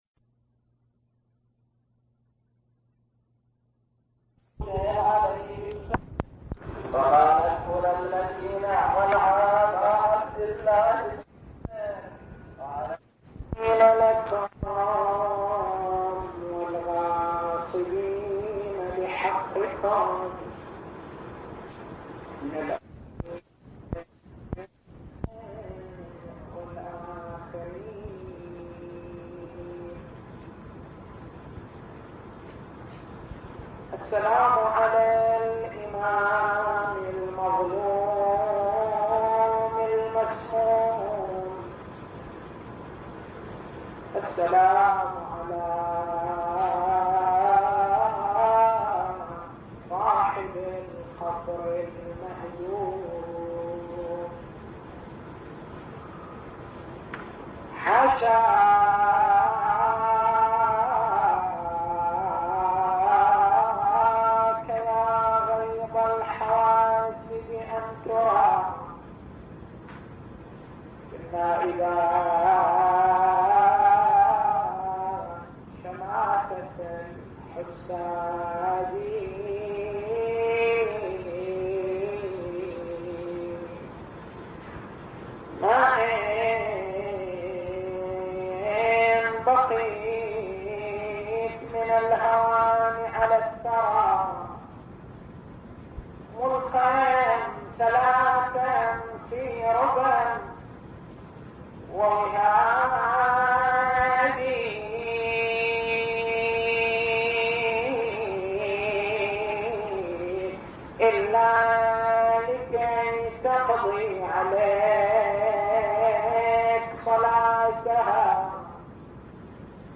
تاريخ المحاضرة: 25/01/1418 التسجيل الصوتي: شبكة الضياء > مكتبة المحاضرات > مناسبات متفرقة > أحزان آل محمّد